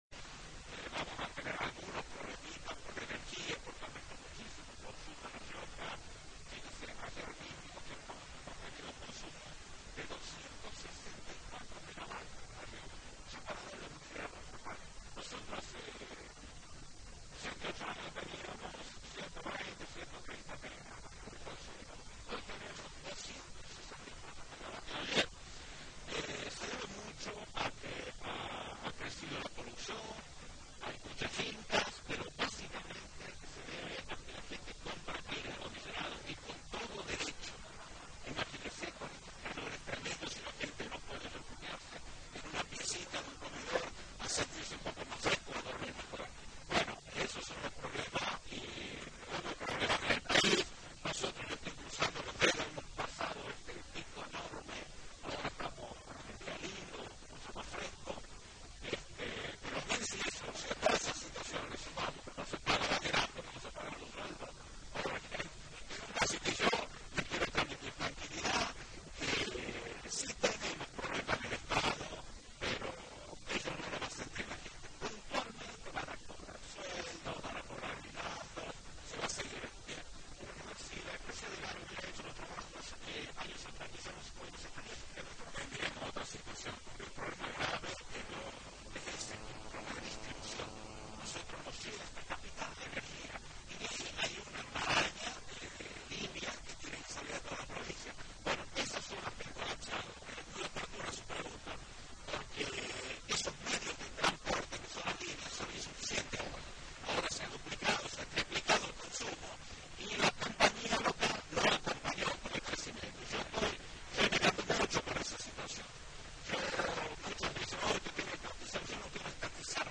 Luis Beder Herrera, gobernador de La Rioja
luis-beder-herrera-gobernador-de-la-rioja1.mp3